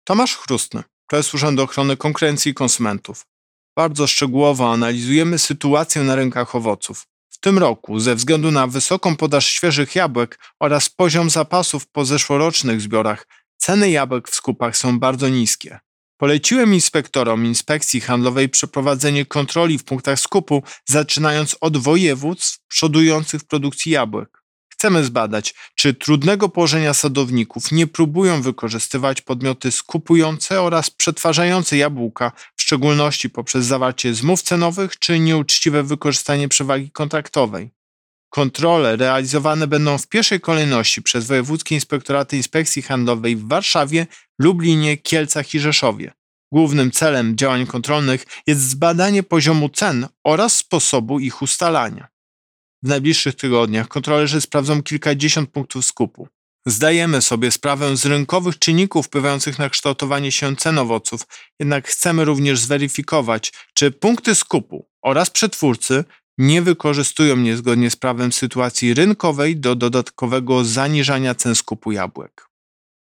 Wypowiedź Prezesa UOKiK Tomasza Chróstnego z 1 września 2021 r..mp3